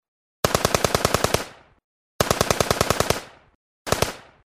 Звуки автомата Калашникова
Звук стрельбы из АК 47